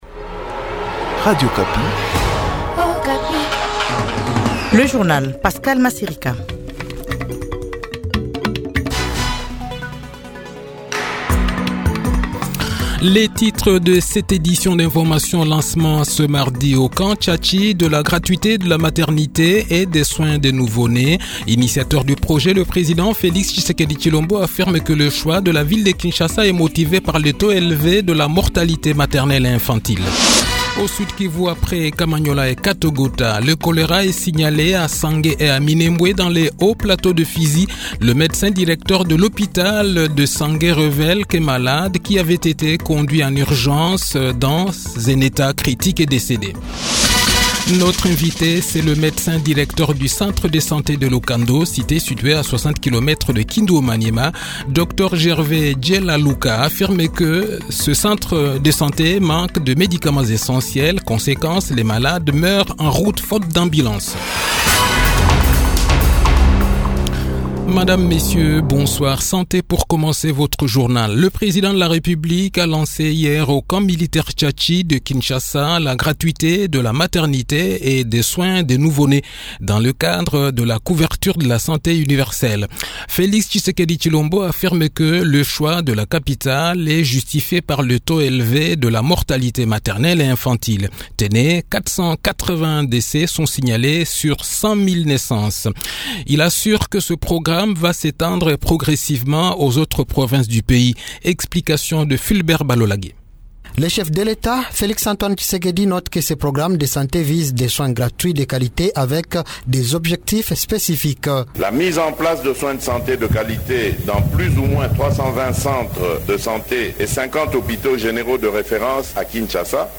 Journal Soir
Le journal de 18 h, 6 Septembre 2023